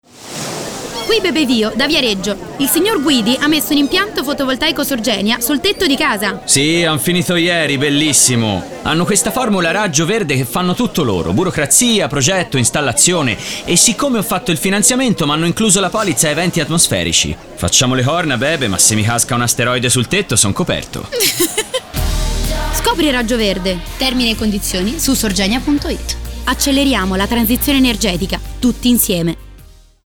Sorgenia: on air la campagna radio dedicata al FV residenziale
Protagonista della campagna radio, che sarà trasmessa sulle principali emittenti nazionali, è Bebe Vio.
Ogni spot della durata di 30 secondi si conclude con la voce di Bebe Vio e lo slogan “Acceleriamo la transizione energetica, tutti insieme”.